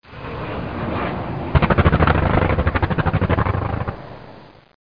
1 channel
SHOT00.mp3